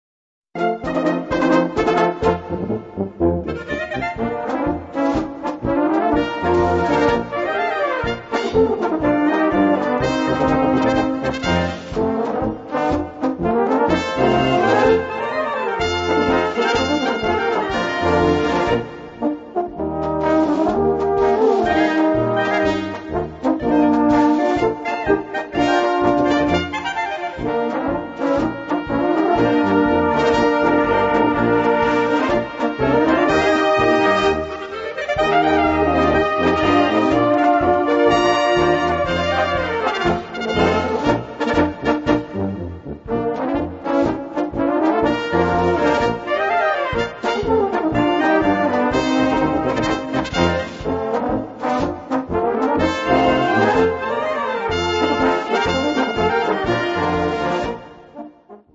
Gattung: Polka Besetzung: Blasorchester Zu hören auf